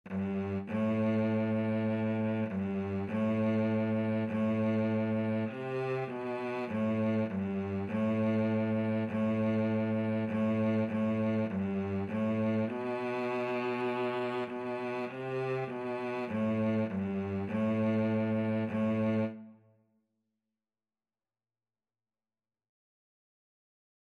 4/4 (View more 4/4 Music)
G3-C4
Cello  (View more Beginners Cello Music)
Classical (View more Classical Cello Music)